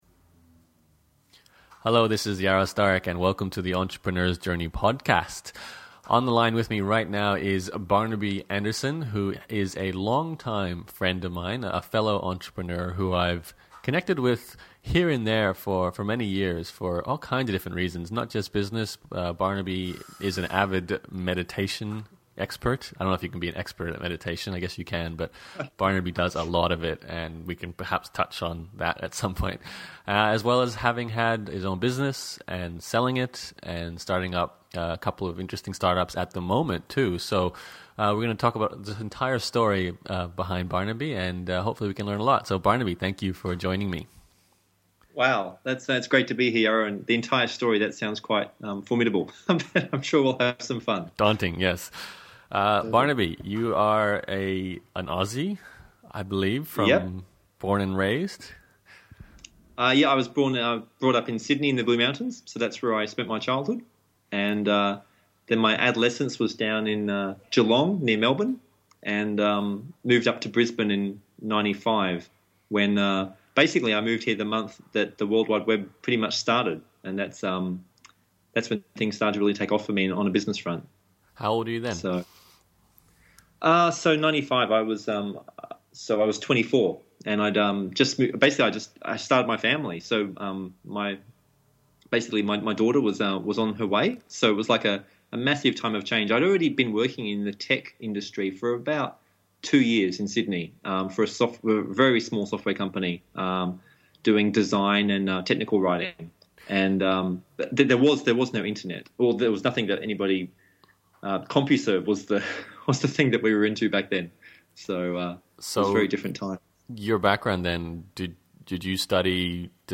This is an interview for anyone who has found themselves in a situation where their business is a trap. You want to leave but you can’t because without you the business doesn’t work, the staff depend on you and you’re so invested in it, you can’t even imagine how to quit.